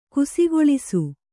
♪ kusigoḷisu